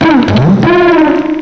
cry_not_garbodor.aif